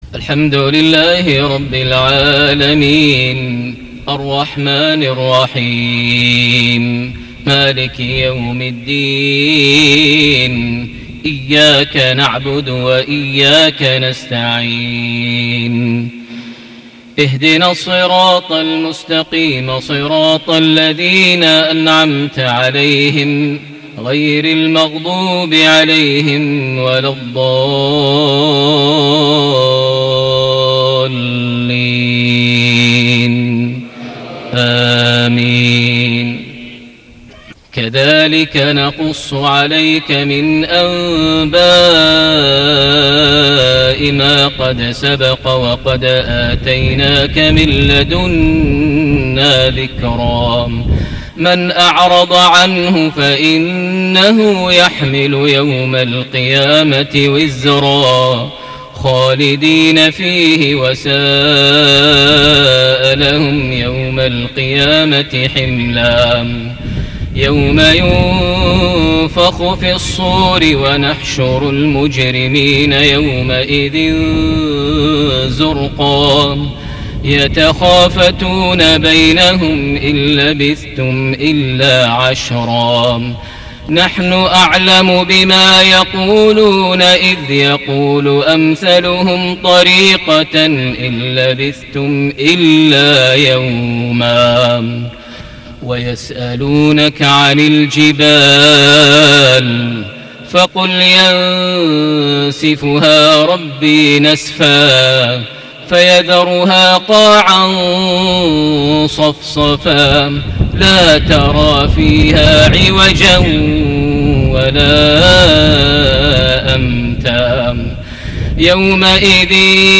صلاة المغرب ١١ جماد الأول ١٤٣٨هـ سورة طه ٩٩-١١٤ > 1438 هـ > الفروض - تلاوات ماهر المعيقلي